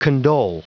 Prononciation du mot condole en anglais (fichier audio)
Prononciation du mot : condole